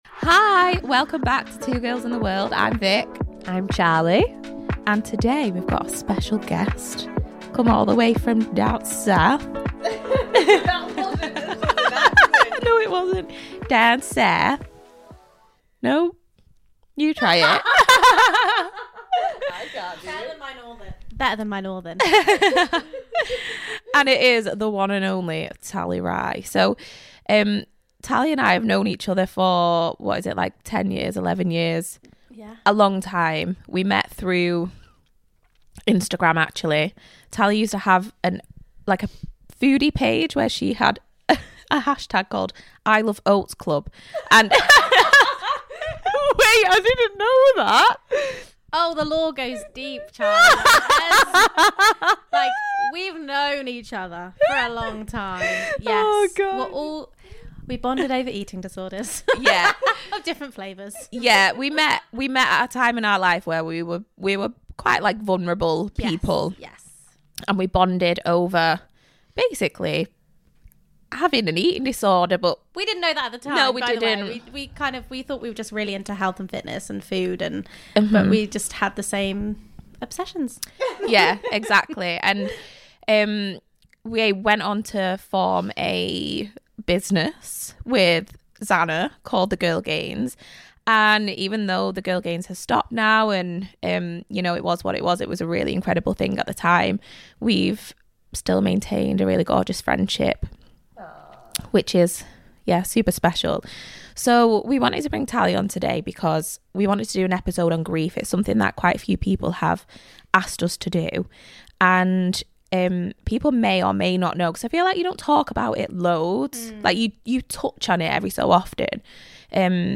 a deep and honest chat about grief. What it looks like, how it changes, and how it shows up in unexpected ways.